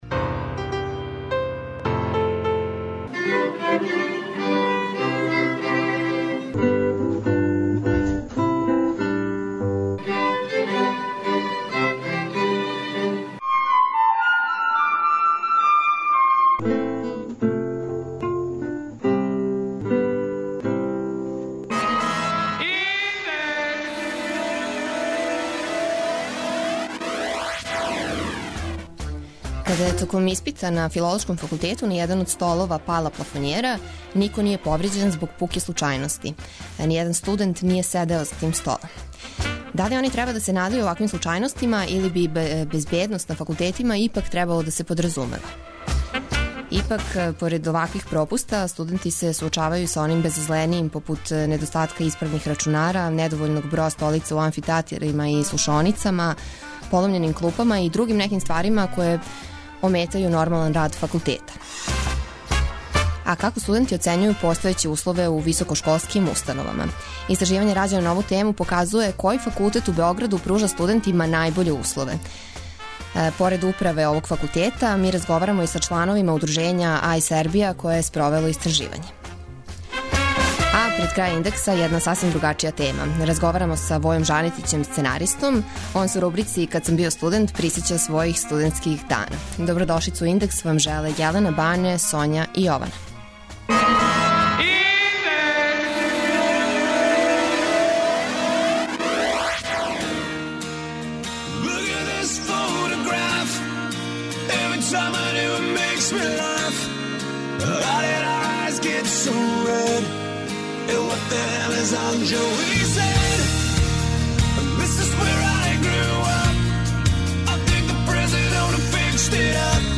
Истраживање рађено на ову тему показује који факултет у Београду пружа студентима најбоље услове, када је у питању инфраструктура, а поред управе овог факултета разговарамо и са члановима удружења iSerbia, које је спровело истраживање.
преузми : 19.00 MB Индекс Autor: Београд 202 ''Индекс'' је динамична студентска емисија коју реализују најмлађи новинари Двестадвојке.